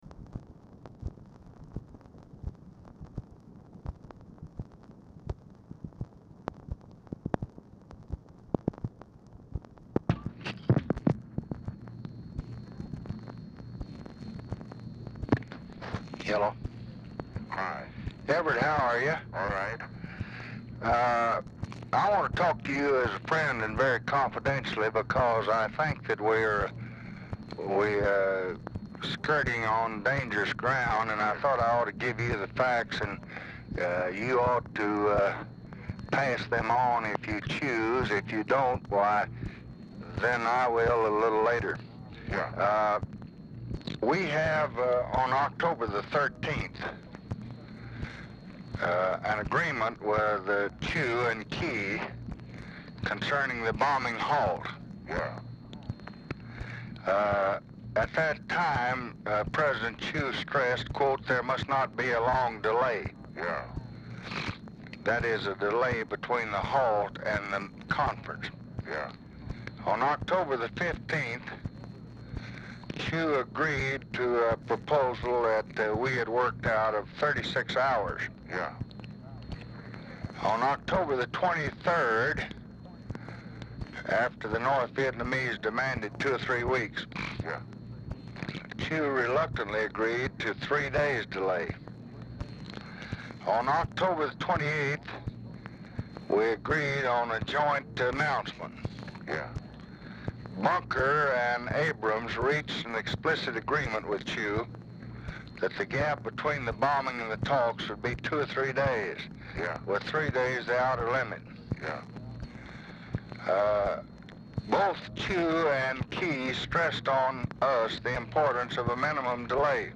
Telephone conversation # 13706, sound recording, LBJ and EVERETT DIRKSEN, 11/2/1968, 9:18PM
Format Dictation belt
Location Of Speaker 1 LBJ Ranch, near Stonewall, Texas